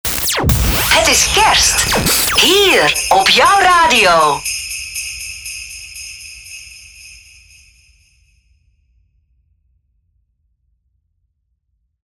• Sound Effects (SFX), zoals hoorbaar in voorbeeld.
• Geluid geoptimaliseerd